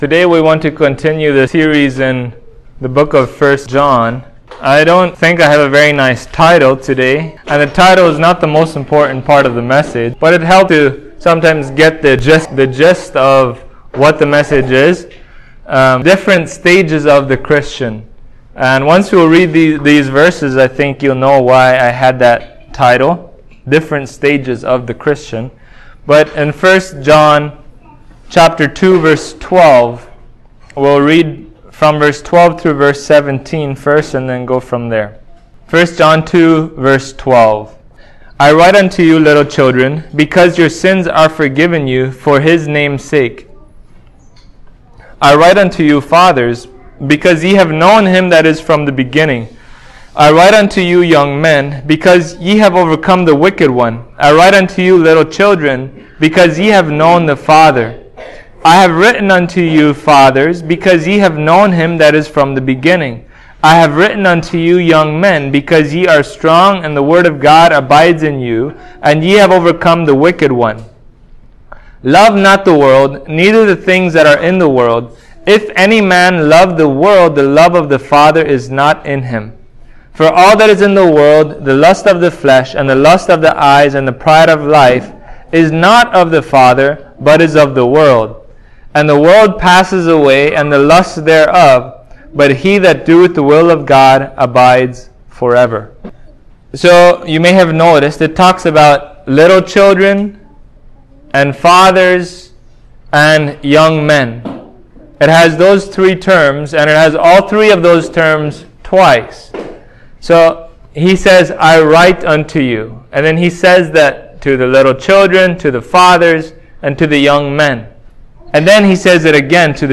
2023 Different Stages of the Christian Preacher